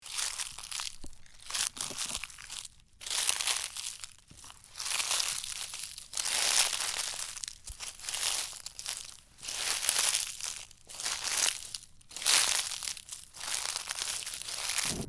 Scary Bush Movement - Bouton d'effet sonore